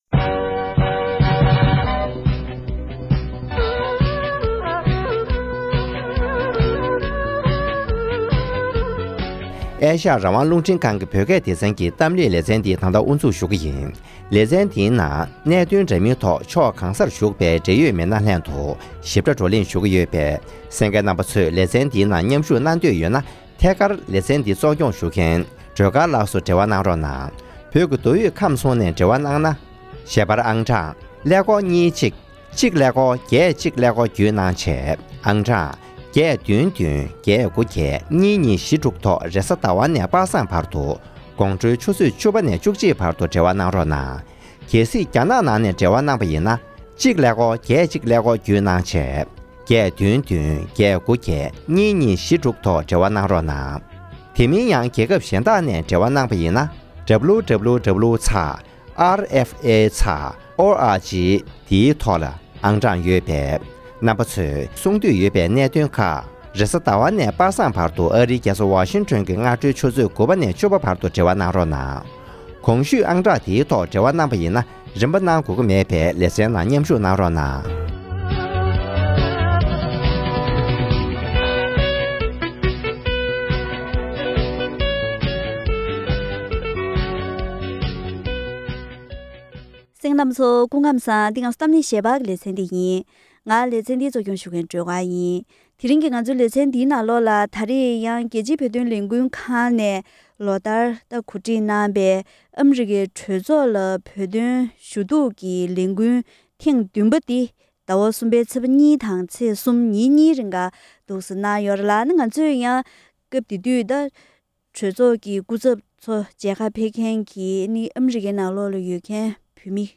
༄༅། །དེ་རིང་གི་གཏམ་གླེང་ཞལ་པར་ལེ་ཚན་འདིའི་ནང་རྒྱལ་སྤྱིའི་བོད་དོན་ལས་འགུལ་ཁང་གིས་གོ་སྒྲིག་གནང་བའི་ཨ་རིའི་གྲོས་ཚོགས་སུ་བོད་དོན་ཞུ་གཏུག་ལས་འགུལ་ལ་མཉམ་ཞུགས་གནང་མཁན་གྱི་མི་སྣ་ཁག་དང་ལྷན་དུ་བཀའ་མོལ་ཞུས་པ་ཞིག་གསན་རོགས་གནང་།